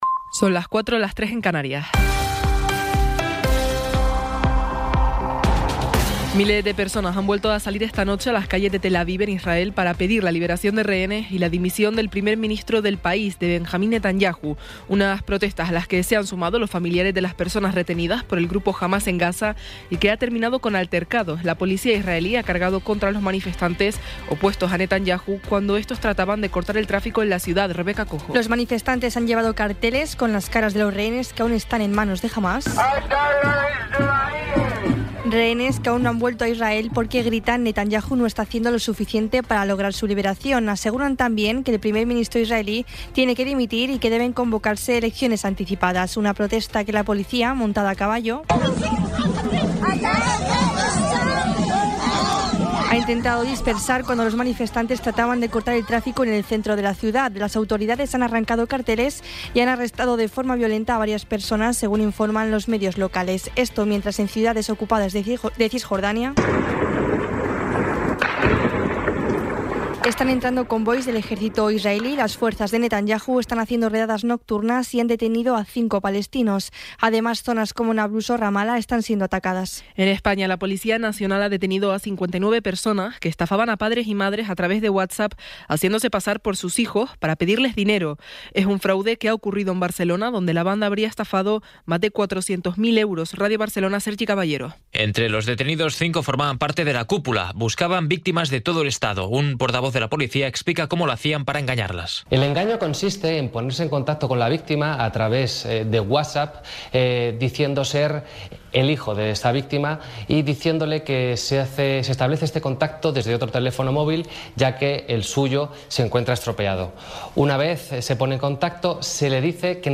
Resumen informativo con las noticias más destacadas del 28 de enero de 2024 a las cuatro de la mañana.